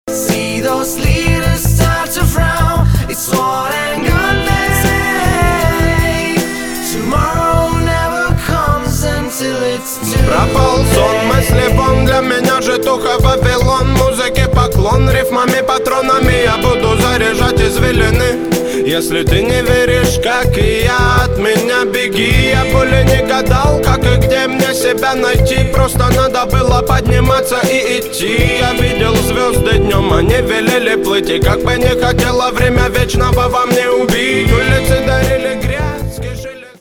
• Качество: 320, Stereo
лирика
речитатив